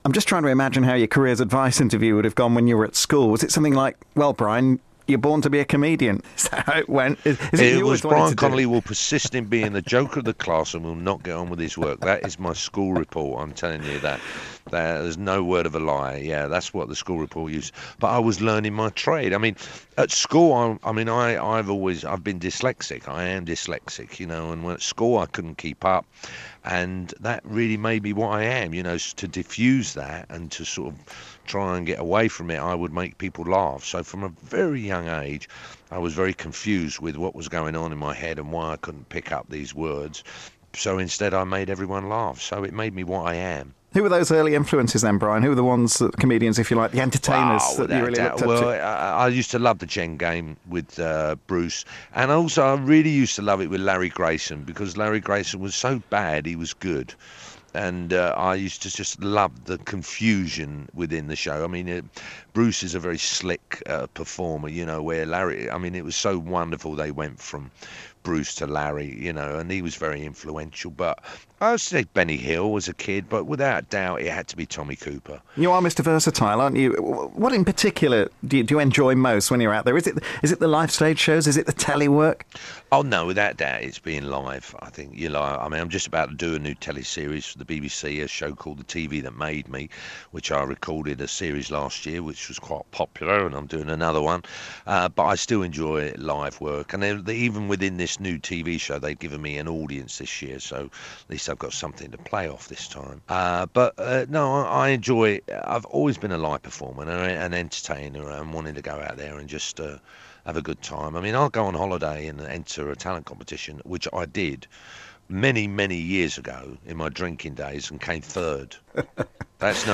Brian chats about his career and forthcoming tour 'Alive and Dangerous'.